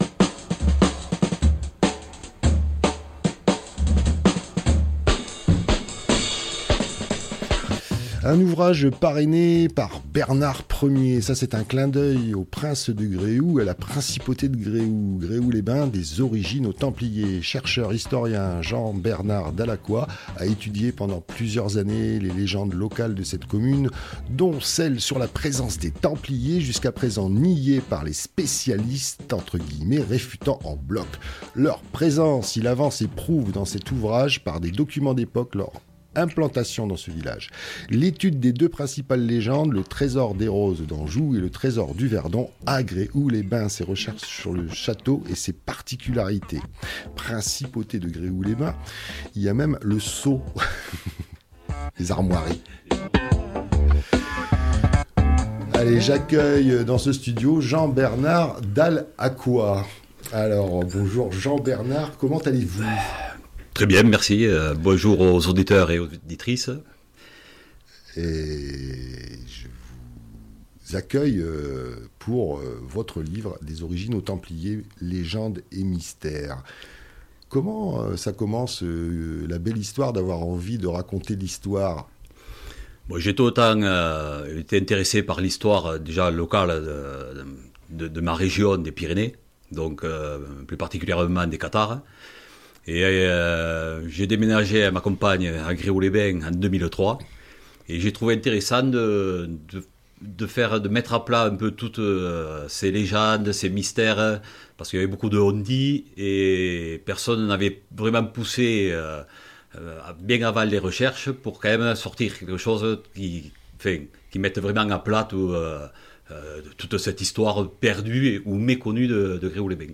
Dans cette entrevue, vous découvrirez un chercheur-historien qui a étudié pendant plusieurs années les légendes locales de Gréoux-les-Bains dont celle sur la présence des Templiers.